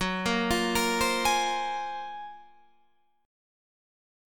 GbM#11 chord